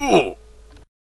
levelup.ogg